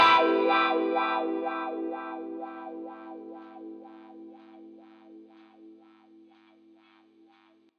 08 Wah Guitar PT4.wav